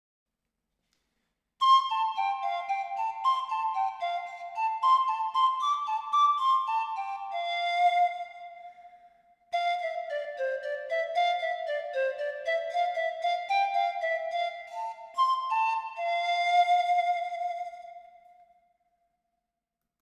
Пан-флейта Gibonus FPS-Tenor Cherry
Пан-флейта Gibonus FPS-Tenor Cherry Тональность: С
Диапазон - три октавы (С1-С4), строй диатонический.